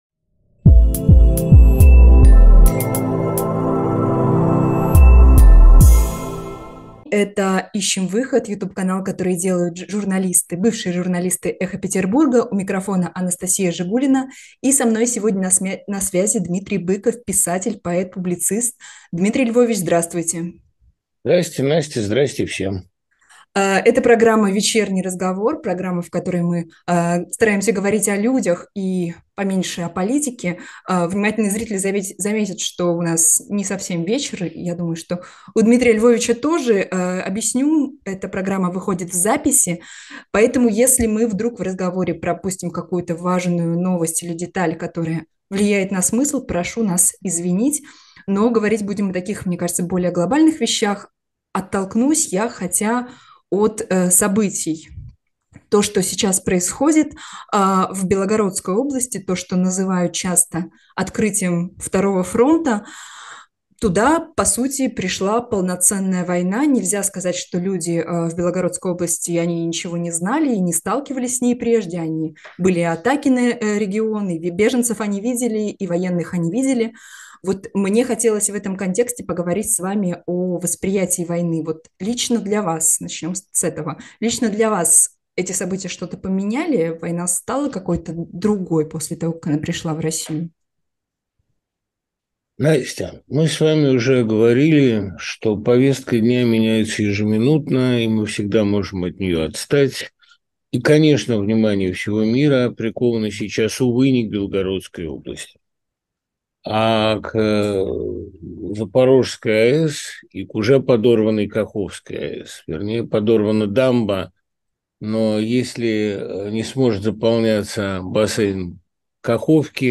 большое вечернее интервью с писателем Дмитрием Быковым